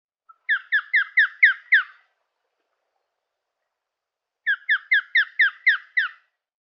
「日本の鳥百科」ミサゴの紹介です（鳴き声あり）。